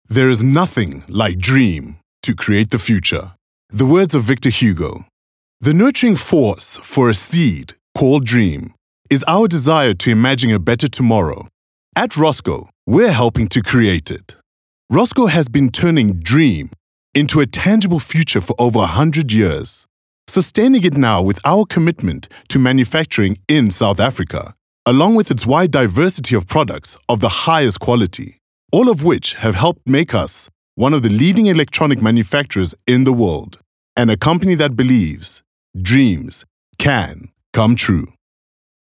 alluring, bold, intimate, rich, sultry
My demo reels